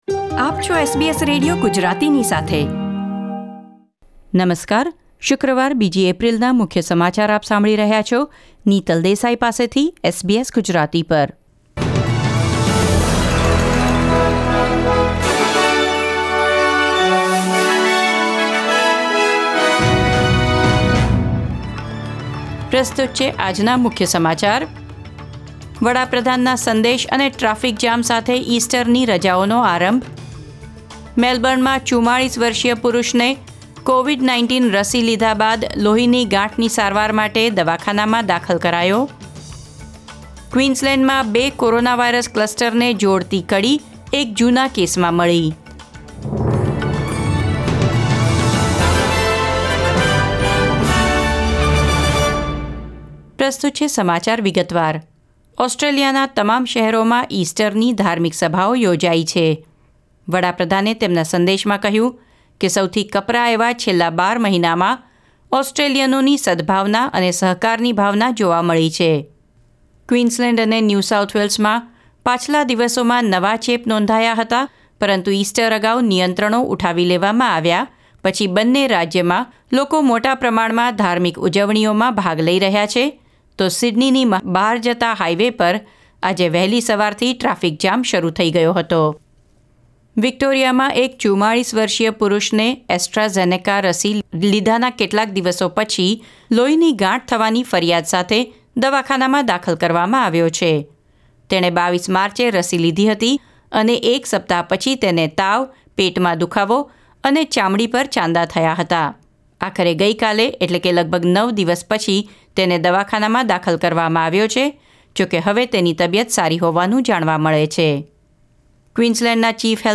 ૨ એપ્રિલ ૨૦૨૧ના મુખ્ય સમાચાર